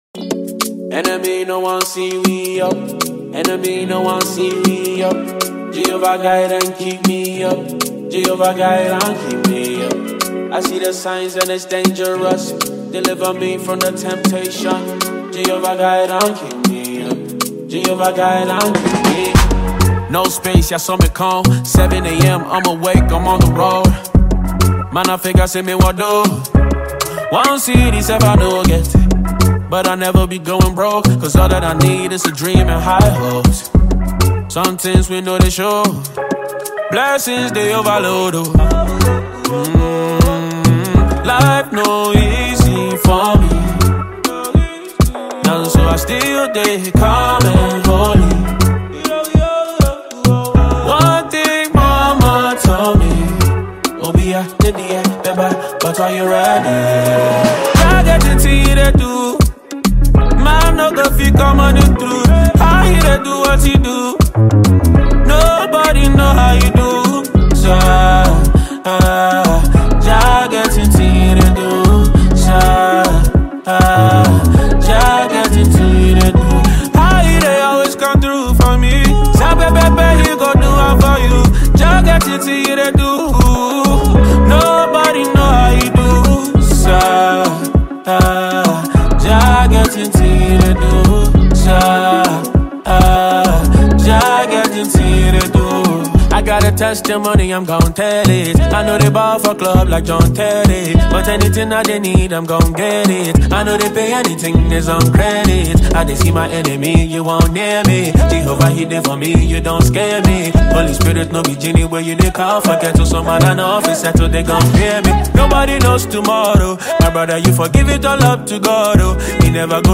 Ghana Music
Ghanaian rapper
with a catchy voice and lyrics